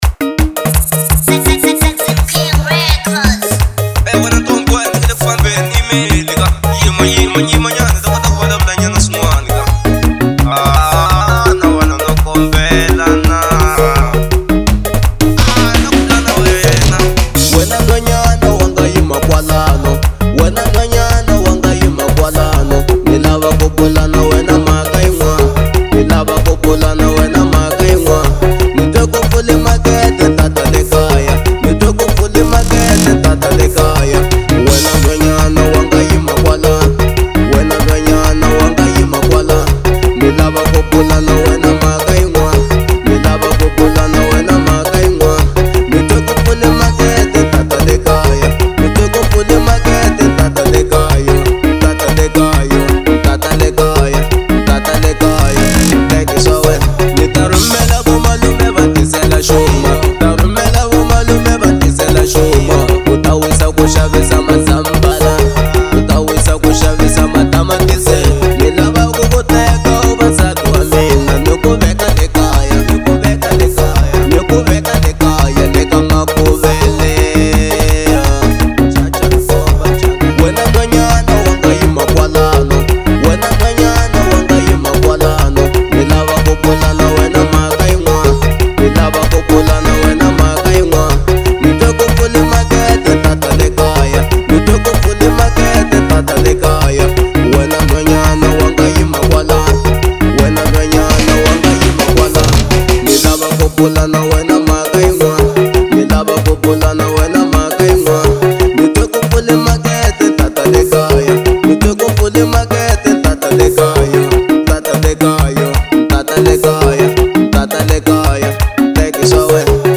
03:25 Genre : Xitsonga Size